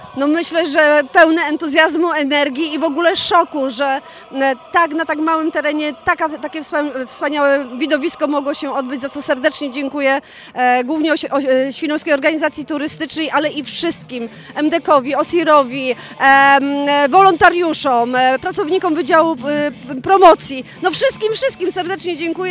Wielkie podziękowania za organizację – złożyła na antenie Twojego Radia Prezydent Świnoujścia Joanna Agatowska. Za nami widowiskowe Dni Twierdzy 2025, które przyciągnęły tłumy turystów oferując atrakcje muzyczne, pokazy bitew i czołgów.